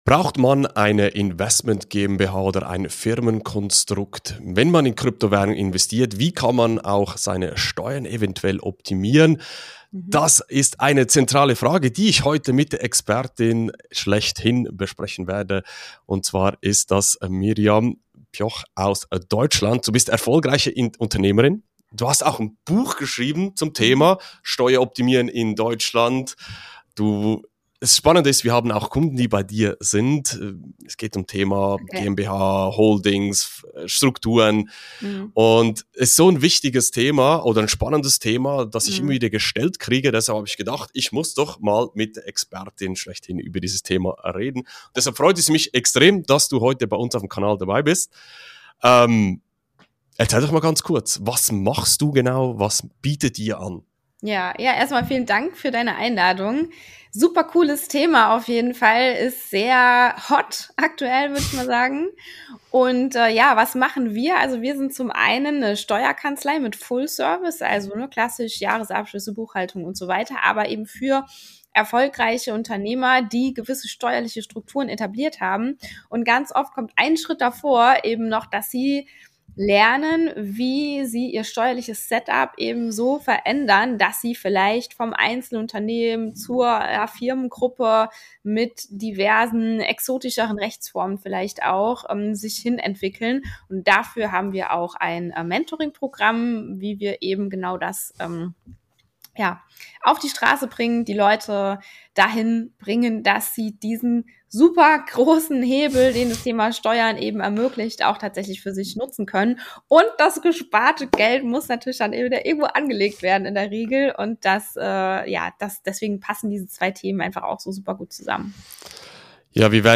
So Krypto-Steuern sparen in Deutschland! (Interview